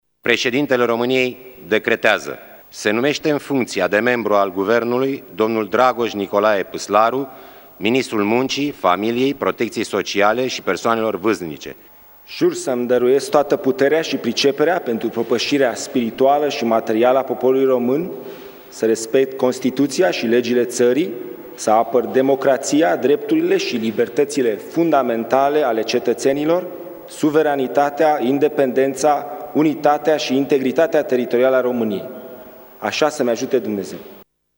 Ceremonie a avut loc la Palatul Cotroceni, în prezenţa preşedintelui Klaus Iohannis.